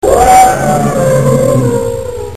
elephant.mp3